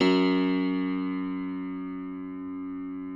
53r-pno04-F0.aif